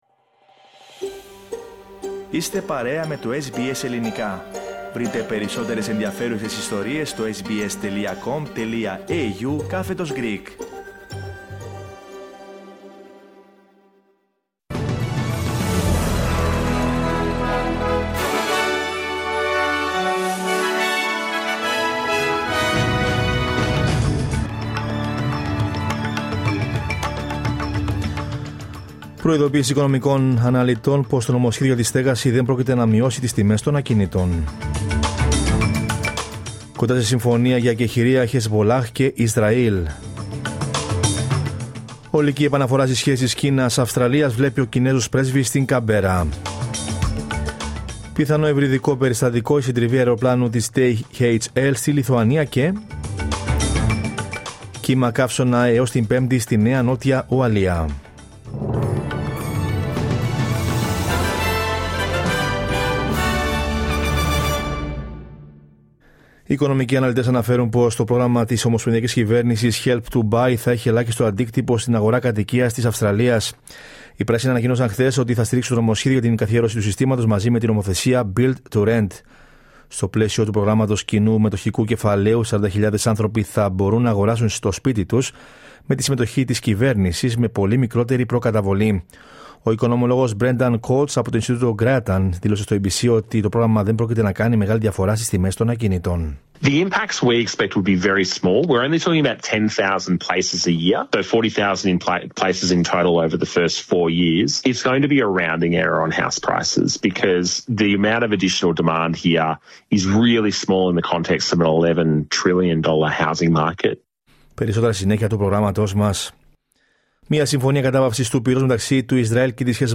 Δελτίο Ειδήσεων Τρίτη 26 Νοεμβρίου 2024